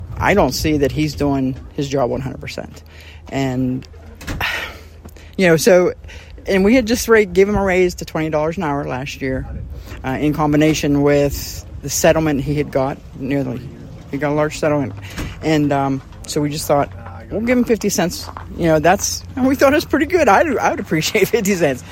Auditor Deb Ferringer said she did not feel Freeman is doing enough to warrant another salary increase.
deb-ferringer-twp-auditor.mp3